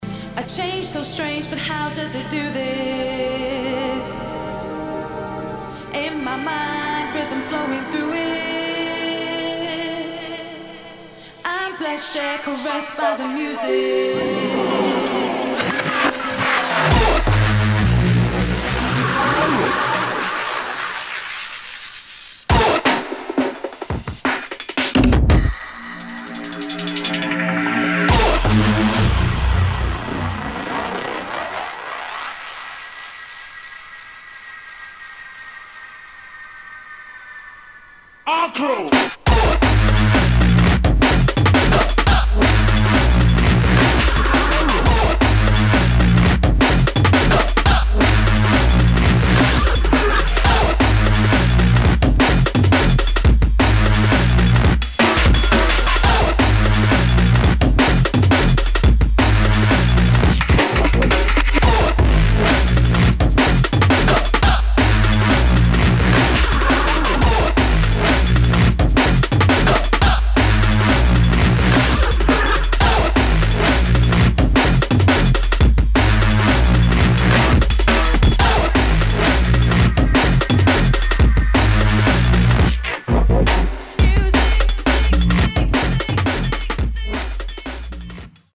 DRUM'N'BASS / JUNGLE